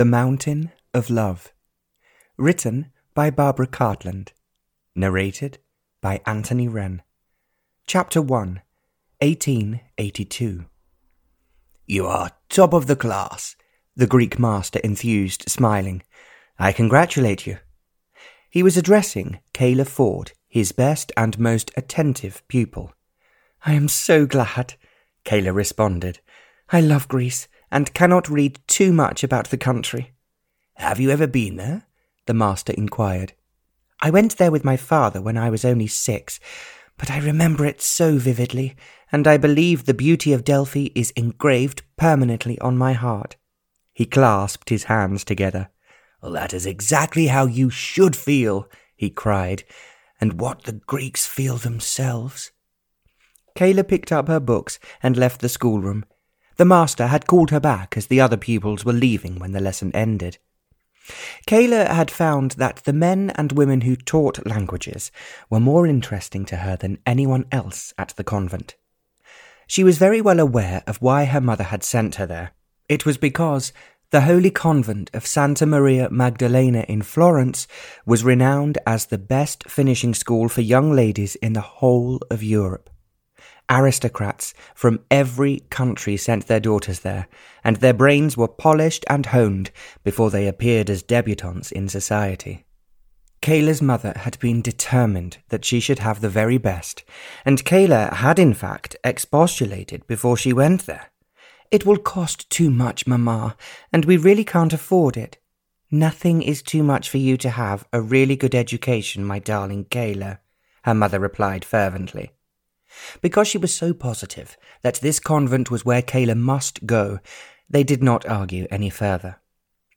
Audiobook The Mountain of Love (Barbara Cartland’s Pink Collection 93), written by Barbara Cartland.
Ukázka z knihy